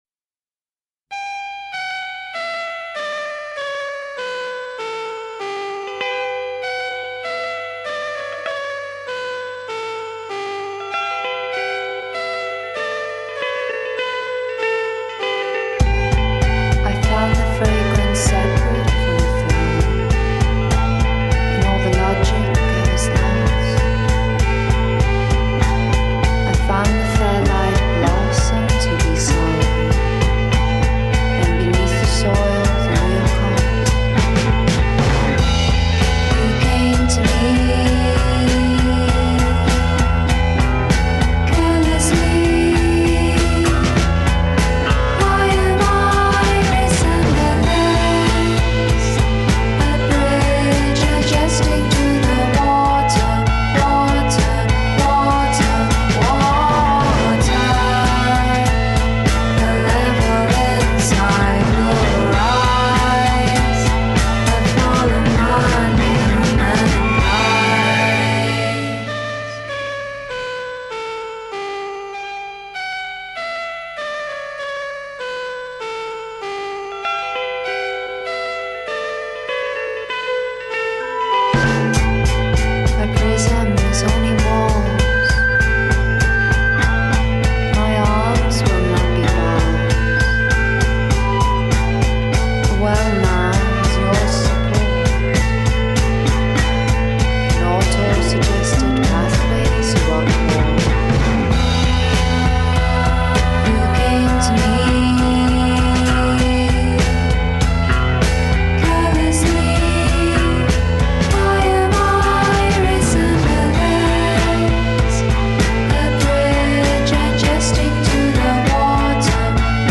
Feel free to blast it at your Halloween party anyway.